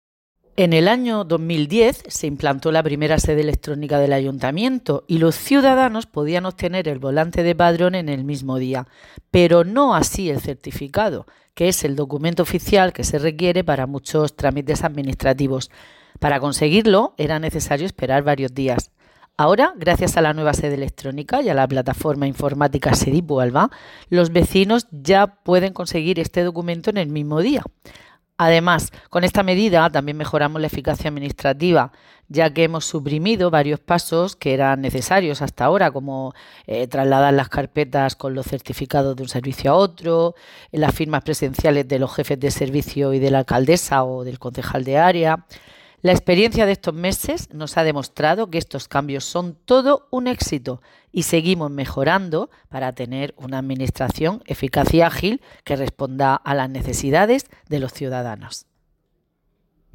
Enlace a Declaraciones Alejandra Guitiérrez